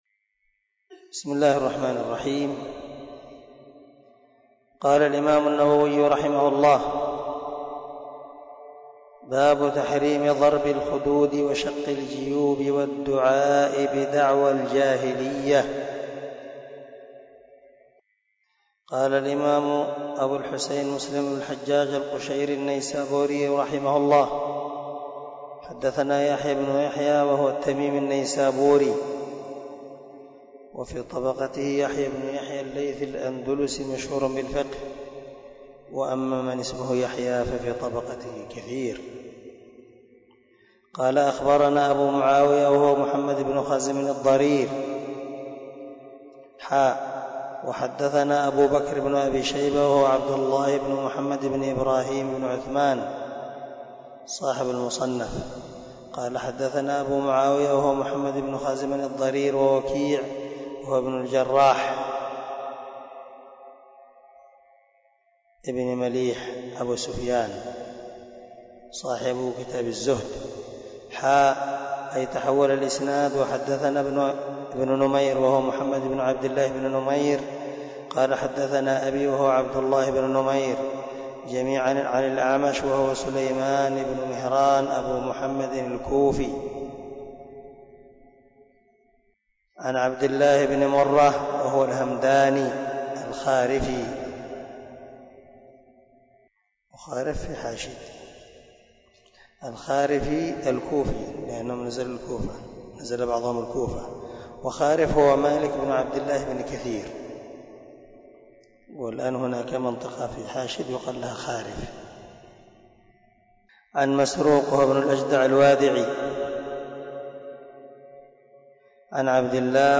071الدرس 70 من شرح كتاب الإيمان حديث رقم ( 103 ) من صحيح مسلم